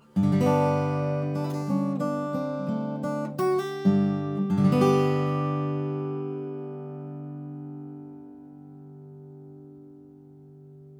Since I have a small variety of mics, and four channels of recording capability, and a T-bar for mounting several mics in close proximity, I decided to attempt to compare some of these mics by recording a very brief clip of solo acoustic guitar.
In order to minimize proximity effect and reduce variations due to small differences in position, I placed the mics 18 inches from the guitar.
I play Hawaiian slack key nearly exclusively, so the guitar is tuned to Open G, and played with bare thumb and fingers.
The room is a rectangular drywall space, light carpeted floor, and two walls lined with vinyl LPs.
Oktava MC012 Omni
Octava-Omni.wav